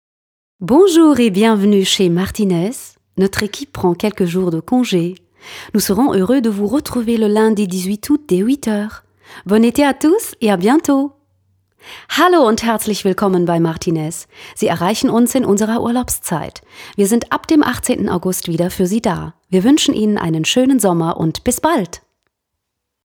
frische, dynamische Erwachsenenstimme
Sprechprobe: Sonstiges (Muttersprache):